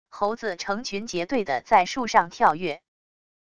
猴子成群结队的在树上跳跃wav音频